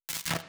SFX_Static_Electricity_Single_03.wav